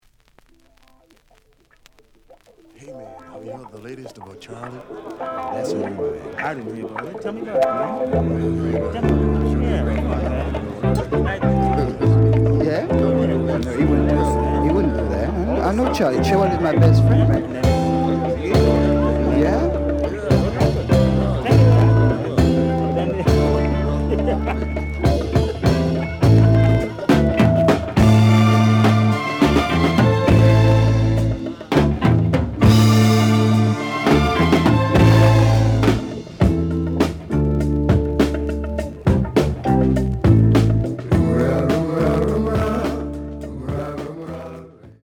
The audio sample is recorded from the actual item.
●Genre: Funk, 70's Funk
Sound cracking on B side.